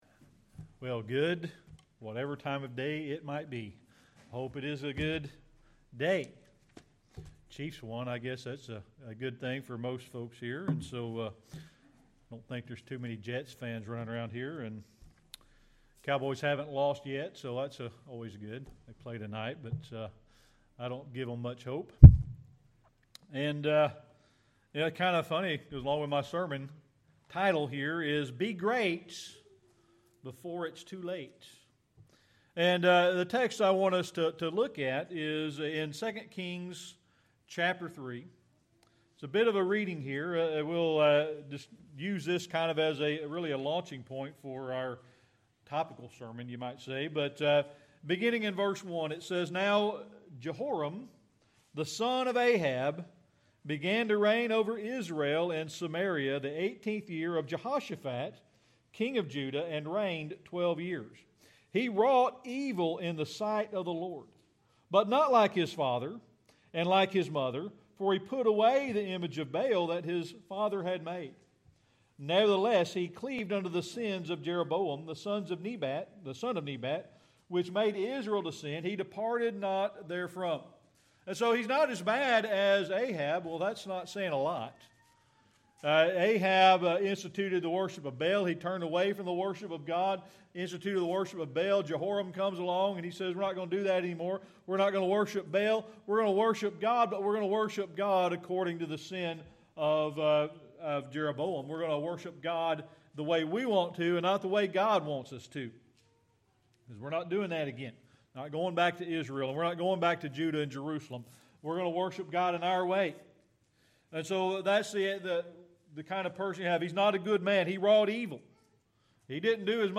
Series: Sermon Archives
2 Kings 3:1-19 Service Type: Sunday Evening Worship Beginning in 2 Kings chapter 3